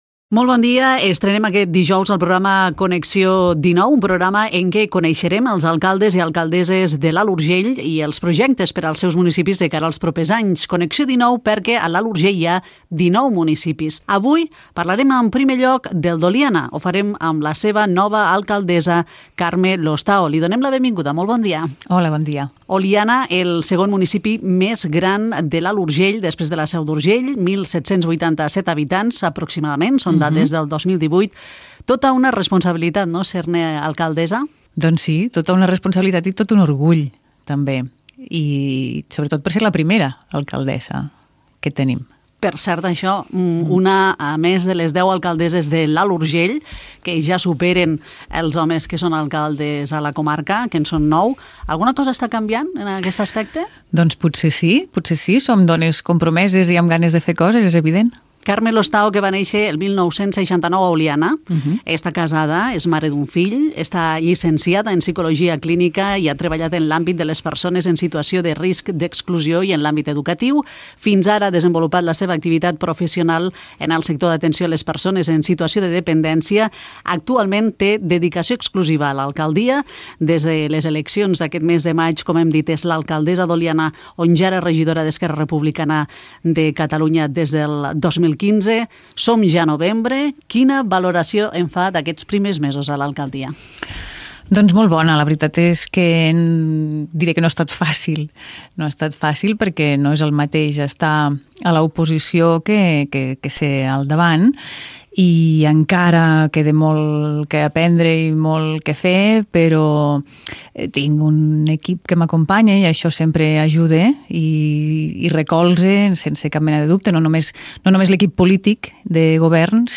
Presentació, entrevista a l'alcaldessa d'Oliana Carme Lostao
Informatiu